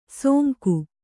♪ sōnku